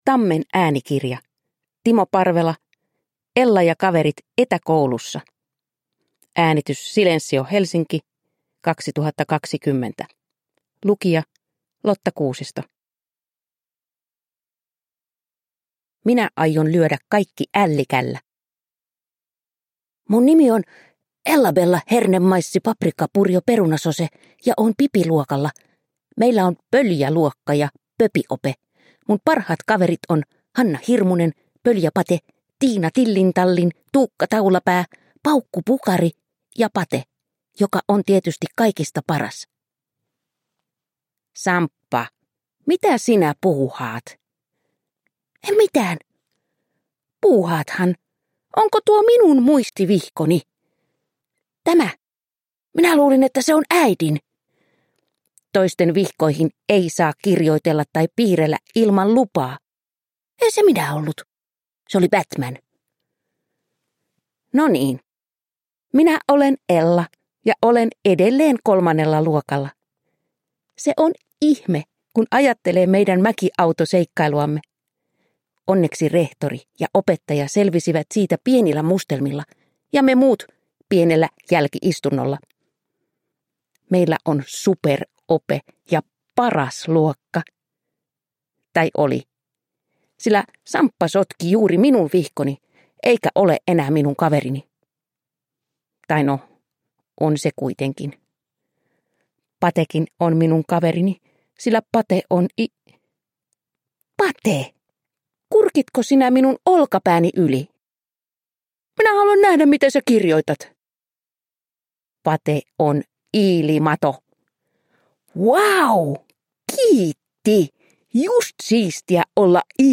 Ella ja kaverit etäkoulussa – Ljudbok – Laddas ner